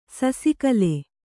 ♪ sasi kale